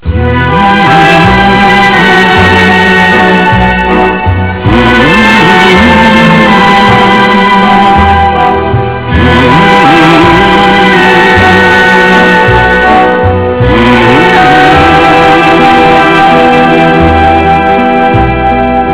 우~우우 우우 하는 노래 있죠?